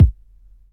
Hot Kick One Shot E Key 201.wav
Royality free kick sound tuned to the E note. Loudest frequency: 125Hz
hot-kick-one-shot-e-key-201-omH.mp3